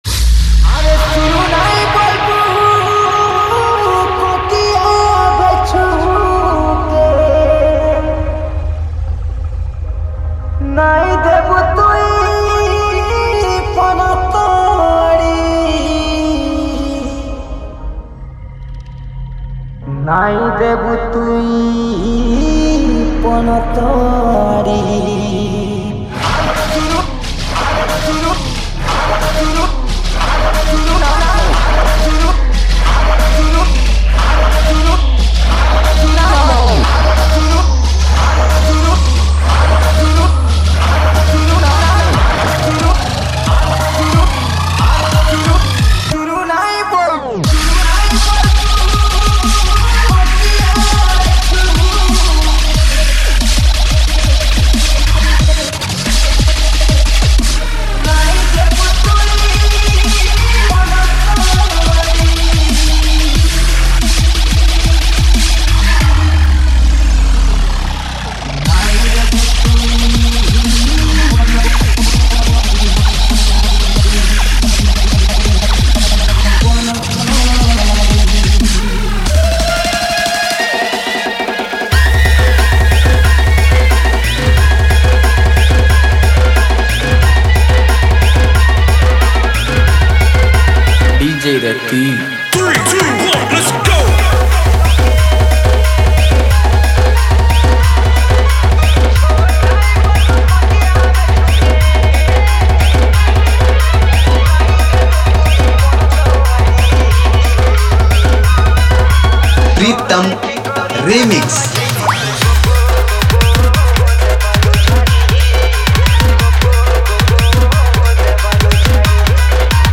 Category: New Sambalpuri Folk Dj Songs 2022